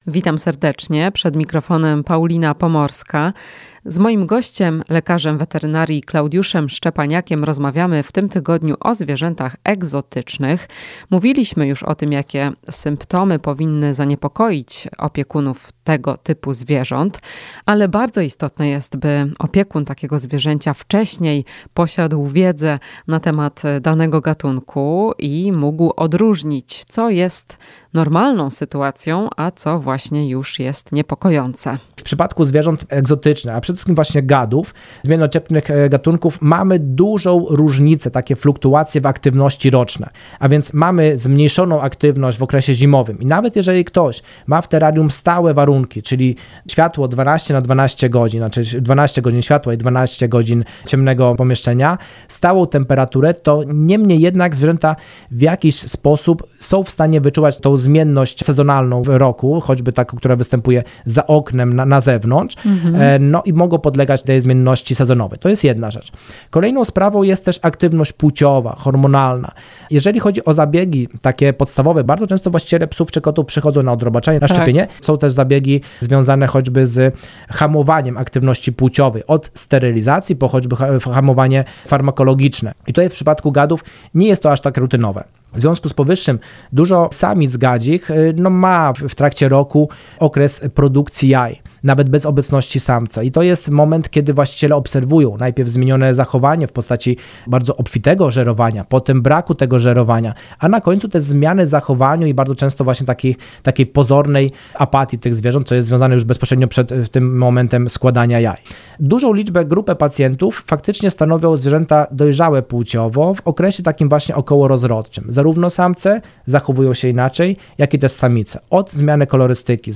Rozmowa z lek. wet.